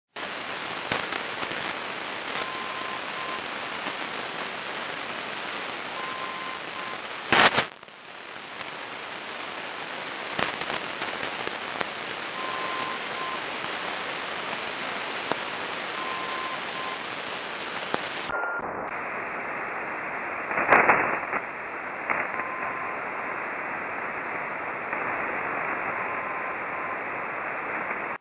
АМ маяк на частоте 3900 кГц.